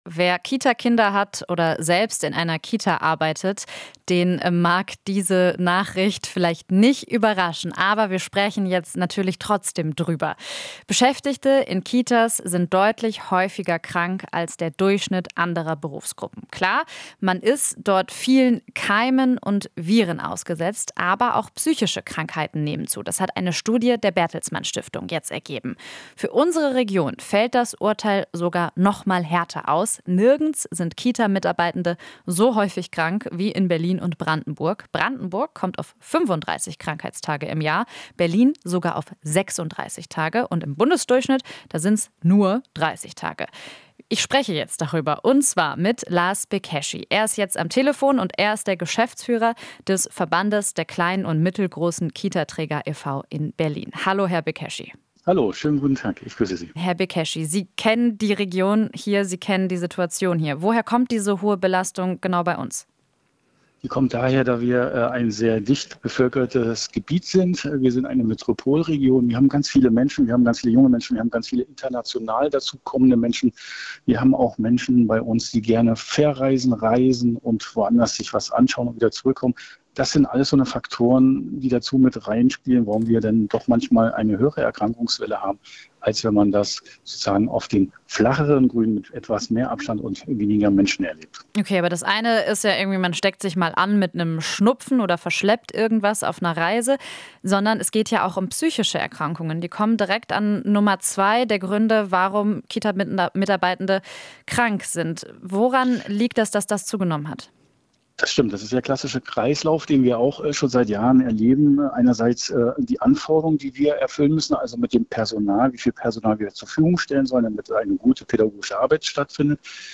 Interview - Kita-Träger: Nicht von Erzieher-Job abschrecken lassen